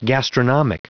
Prononciation du mot gastronomic en anglais (fichier audio)
Prononciation du mot : gastronomic